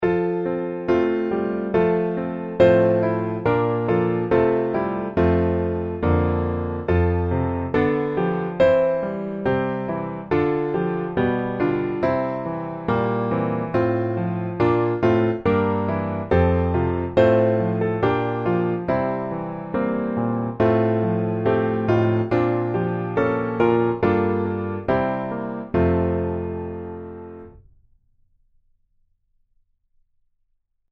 F Majeur